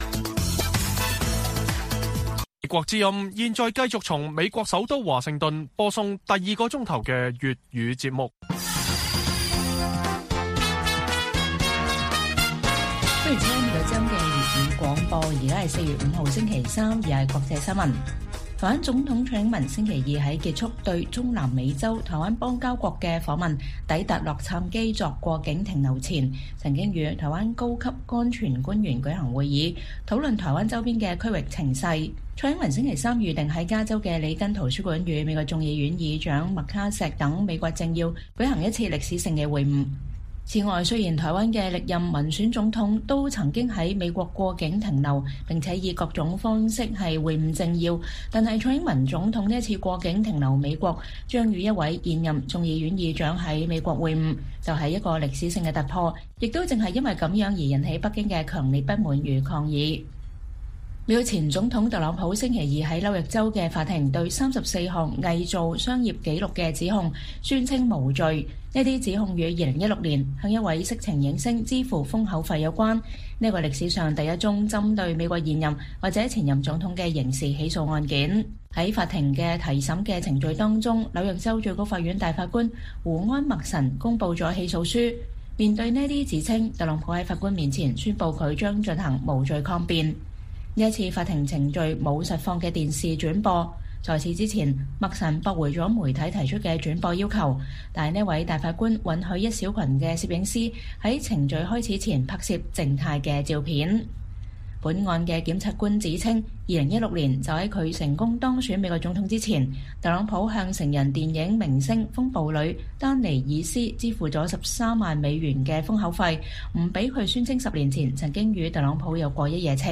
粵語新聞 晚上10-11點: 蔡英文在與麥卡錫舉行歷史性會晤前曾就台灣周邊局勢召開安全會議